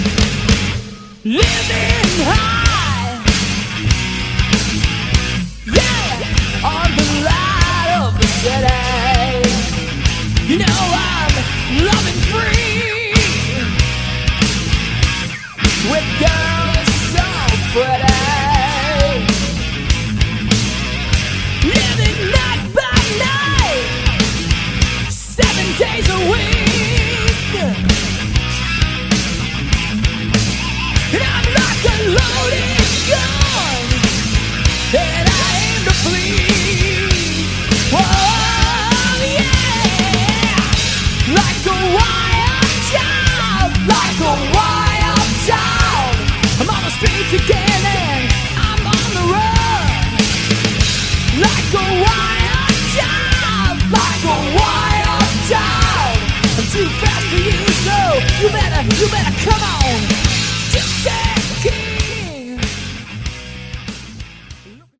Category: Hard Rock
vocals
guitars, keyboards
bass
drums